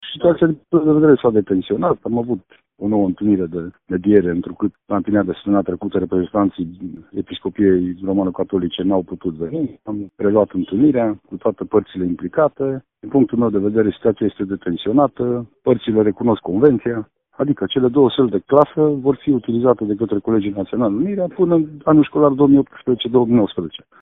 prefect.mp3